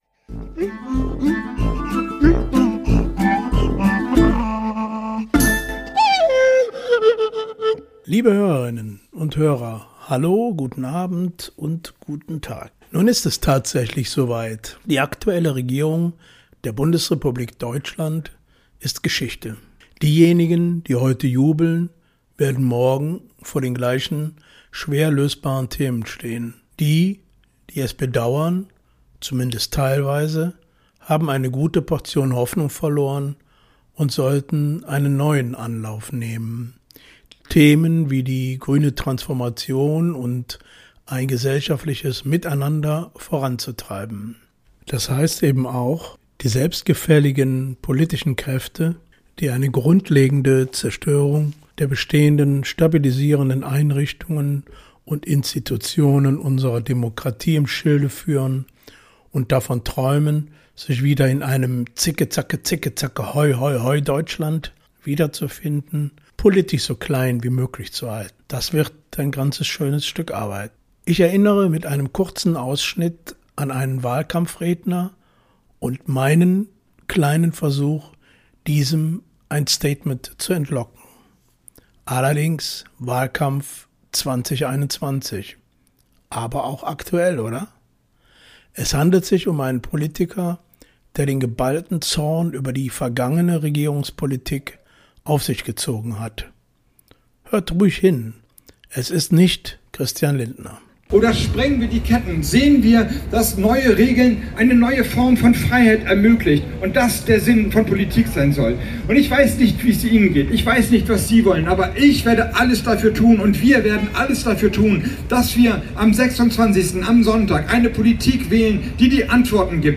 Musik Jazz